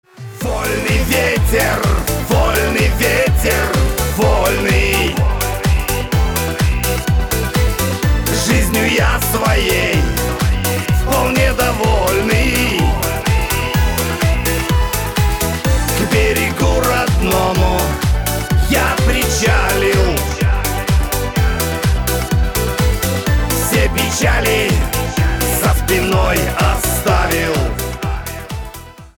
мужской вокал
русский шансон
хриплый голос